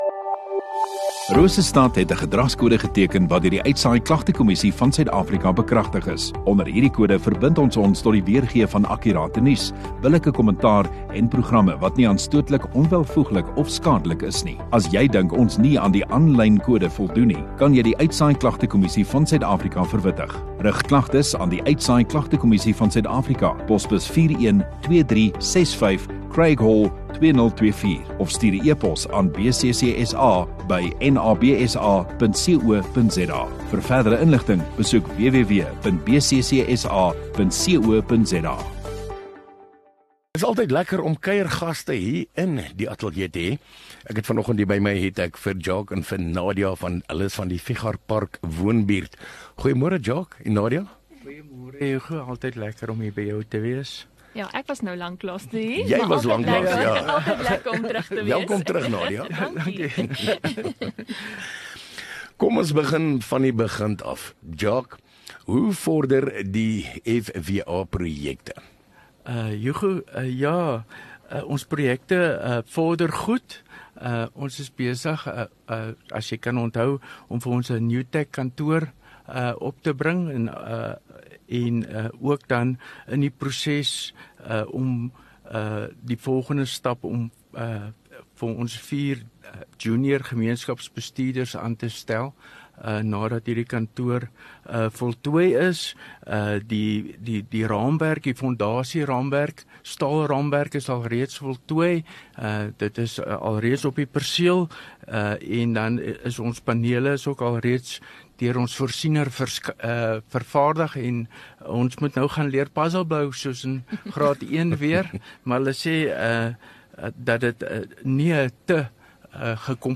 View Promo Continue Radio Rosestad Install Gemeenskap Onderhoude 16 Oct Fichardtpark Woonbuurt Assosiasie - FWA Projekte 10 MIN Download (4.9 MB) AF SOUTH AFRICA 00:00 Playback speed Skip backwards 15 seconds